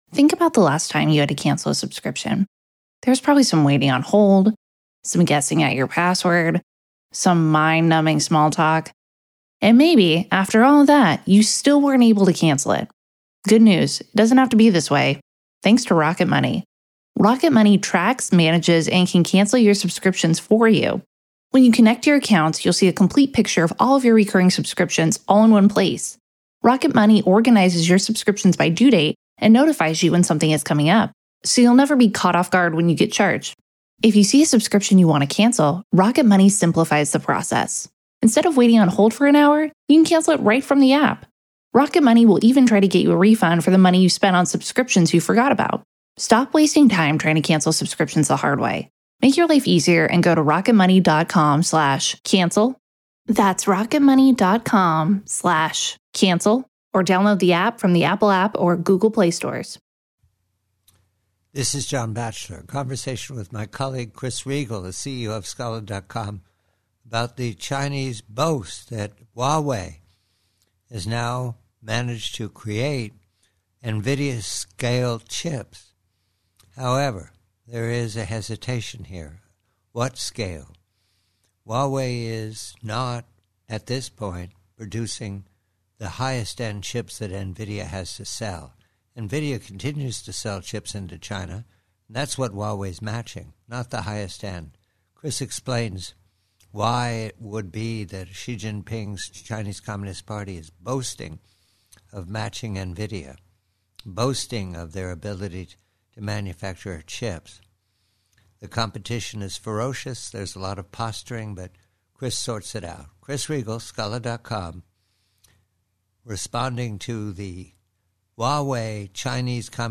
PREVIEW: PRC: HUAWEI: NVIDIA: Conversation